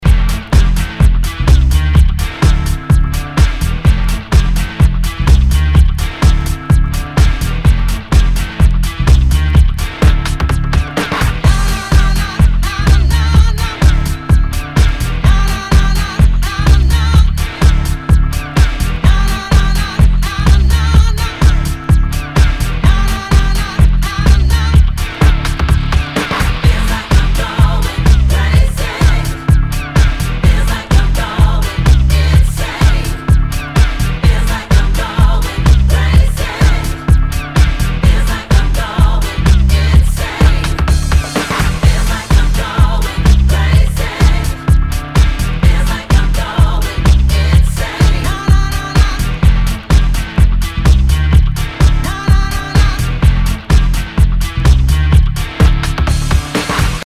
HOUSE/TECHNO/ELECTRO
ディープ・ヴォーカル・ハウス！